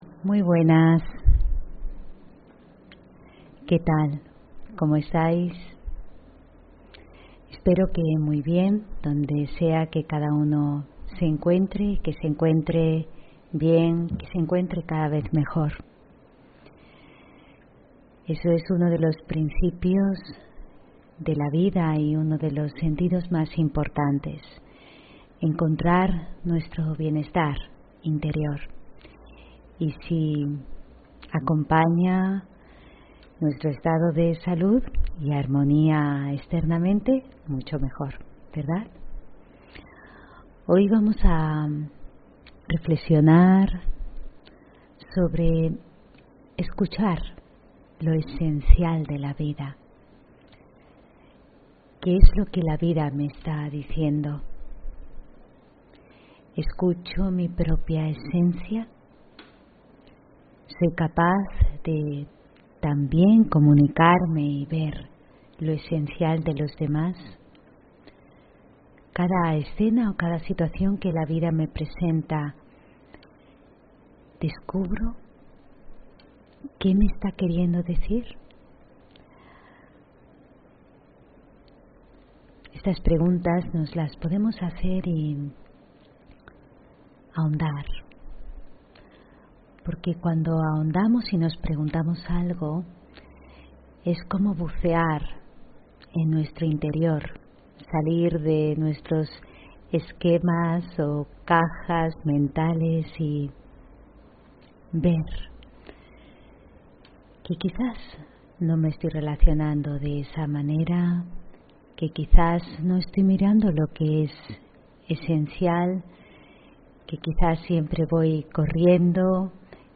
Meditación Raja Yoga y charla: Disfrutar de la soledad (13 Diciembre 2020) On-line desde ...